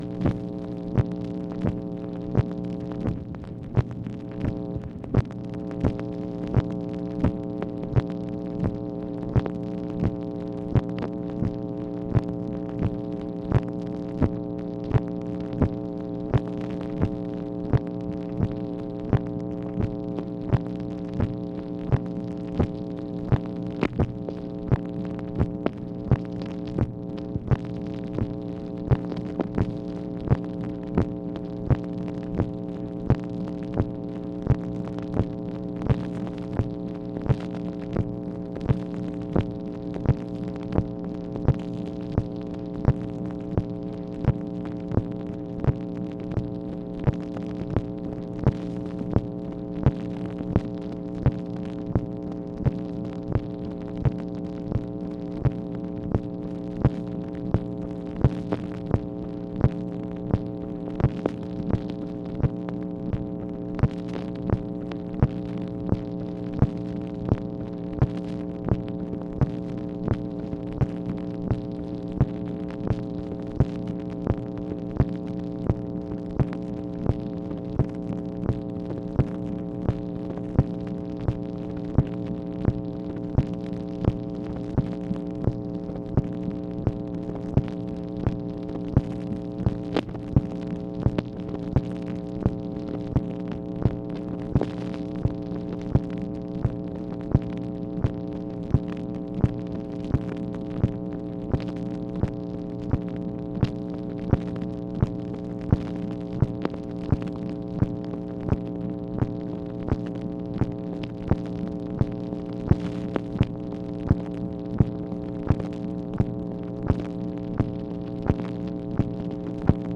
MACHINE NOISE, April 30, 1965
Secret White House Tapes | Lyndon B. Johnson Presidency